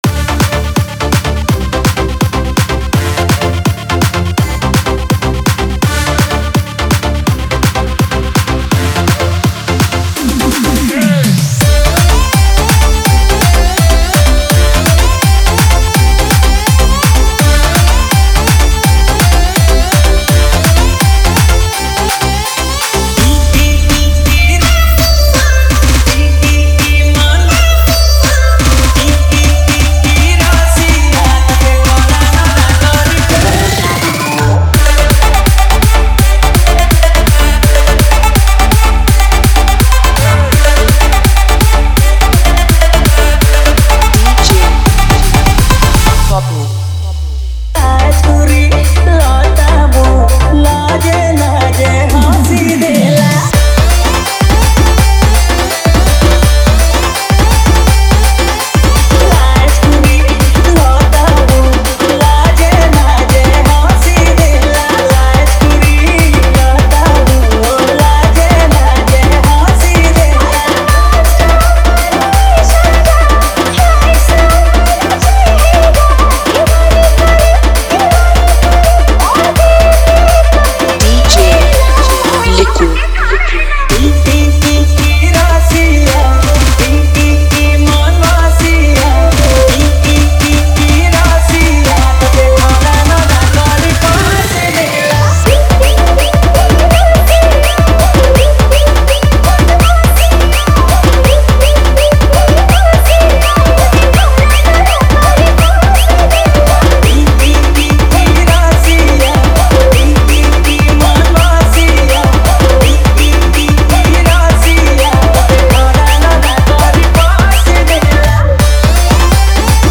Edm X Tapori